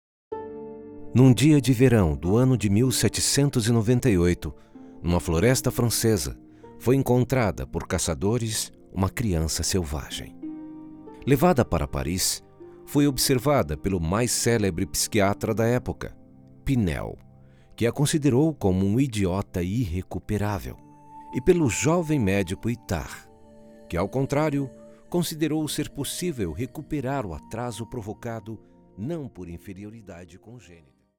offering a confident, versatile baritone voice
Documentaries
Neumann tlm103, Audio Technica AT 4033, Avalon vt737SP, Audient Id14, Yamaha HS50, Mac Mini M1